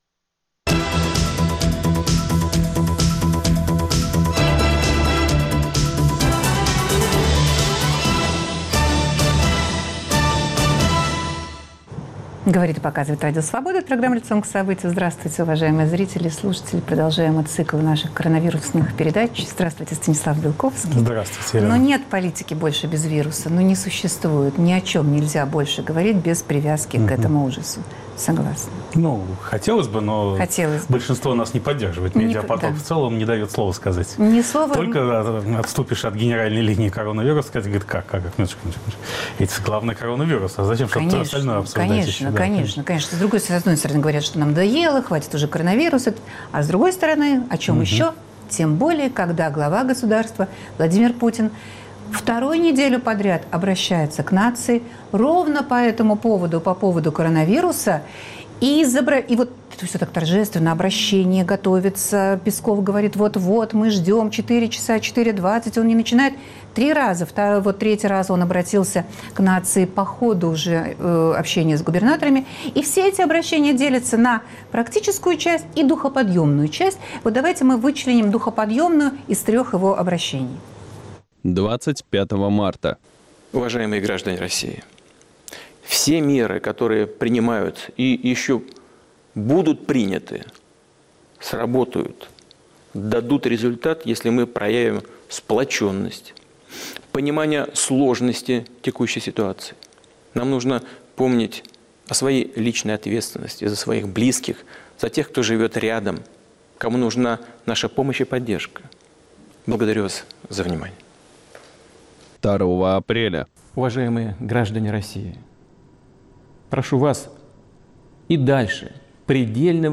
Почему власть решила ослабить карантинные меры? В эфире политолог Станислав Белковский и директор совхоза имени Ленина Павел Грудинин.